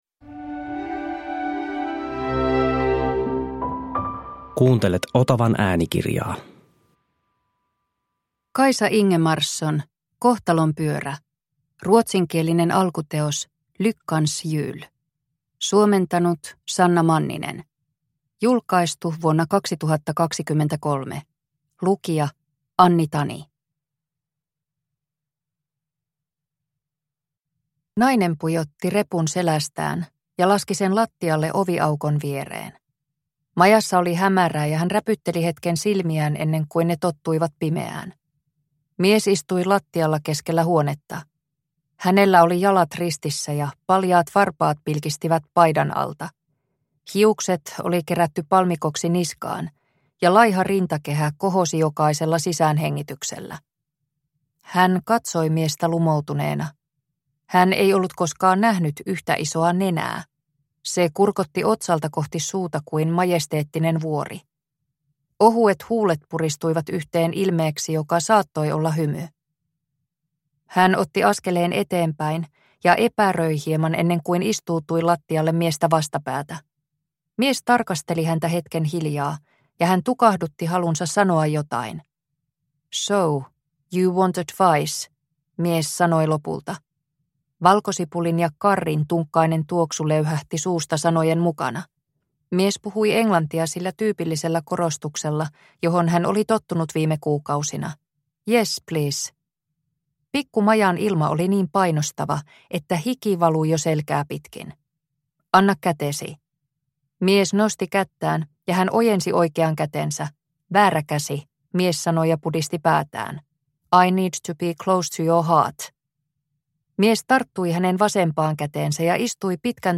Kohtalonpyörä – Ljudbok – Laddas ner